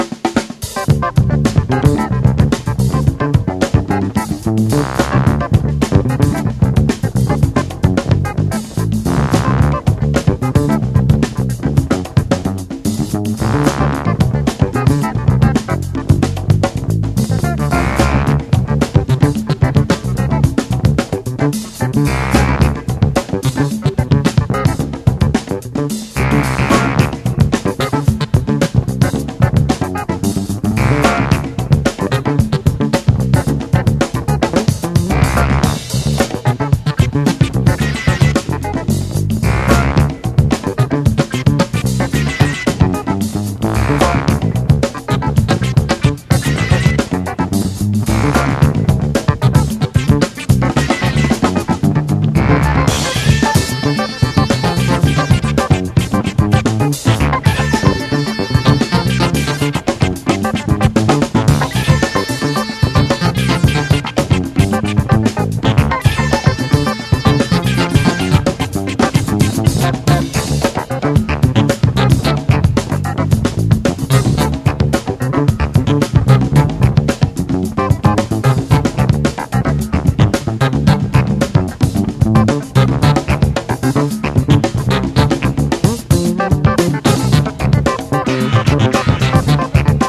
EASY LISTENING / OST / CHILDREN / SOFT ROCK / 口笛